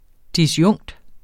Udtale [ disˈjɔŋˀd ]